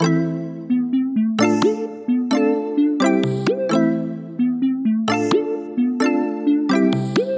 Tag: 90 bpm Chill Out Loops Synth Loops 3.59 MB wav Key : E